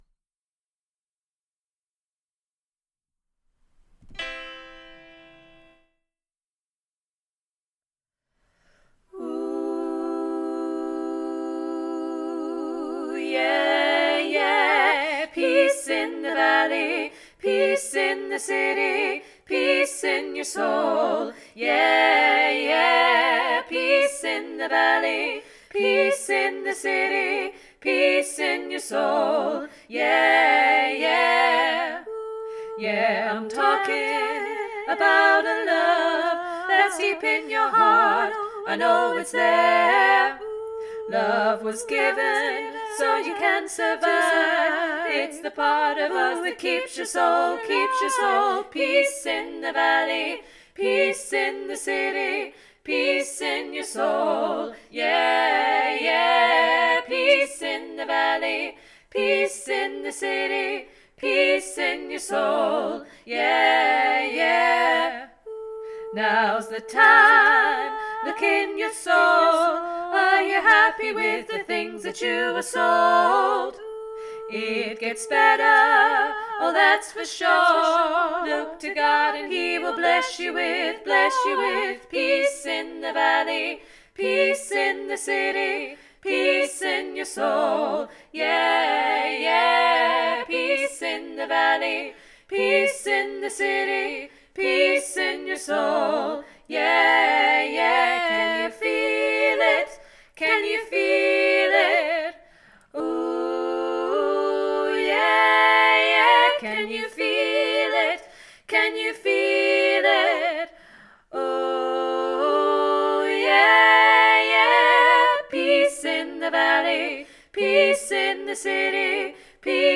Peace 3VG Revamp NO BASS - Three Valleys Gospel Choir
Peace 3VG Revamp NO BASS